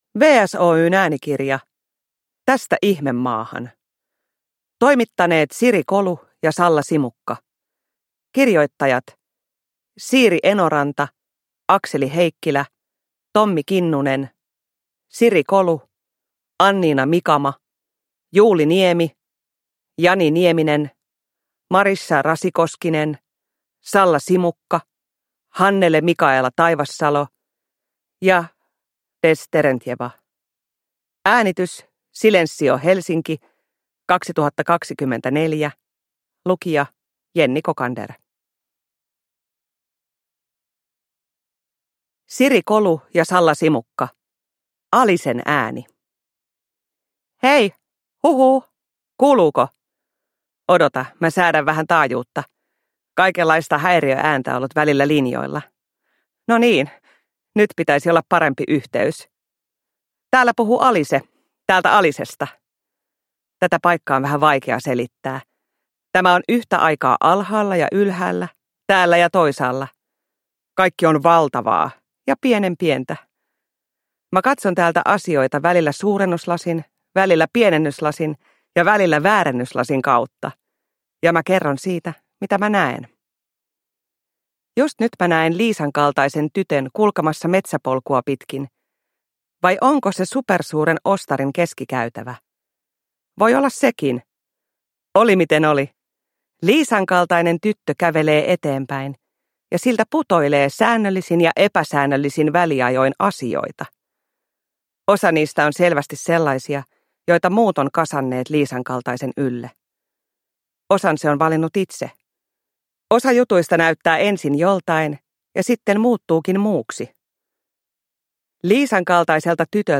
Tästä Ihmemaahan (ljudbok) av Hannele Mikaela Taivassalo